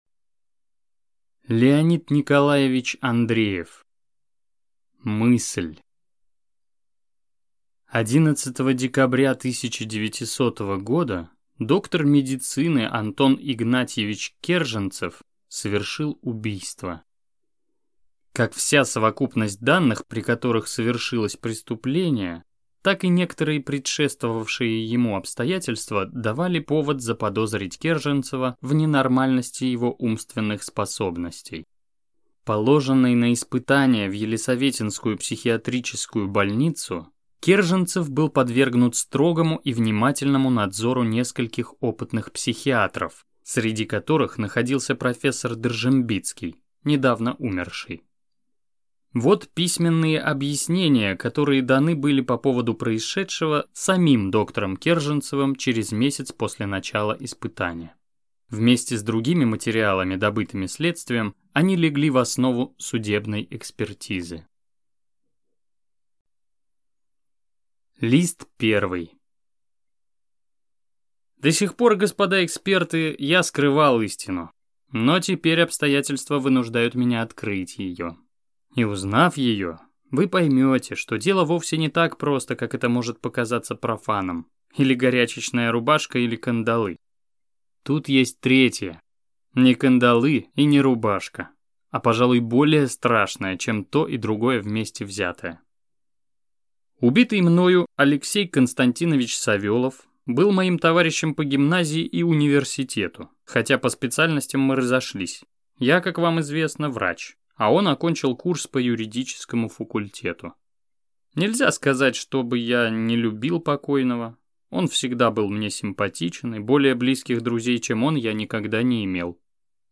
Аудиокнига Мысль | Библиотека аудиокниг